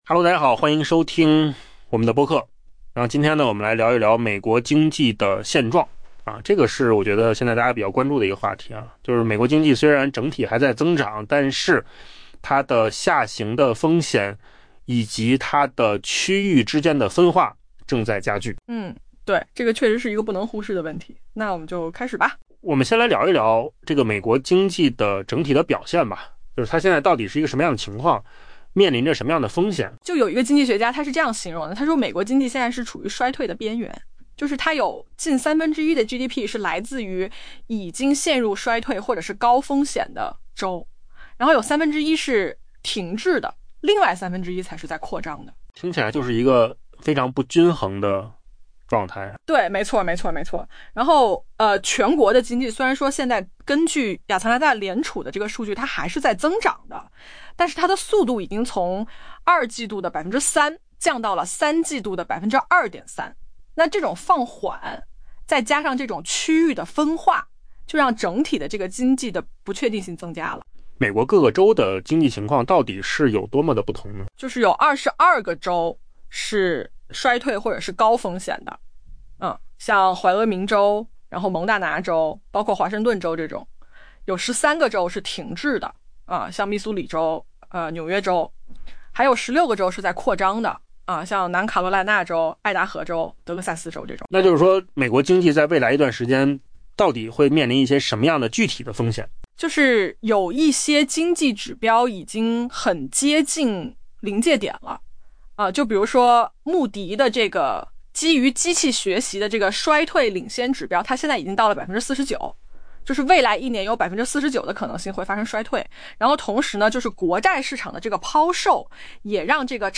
音频由扣子空间生成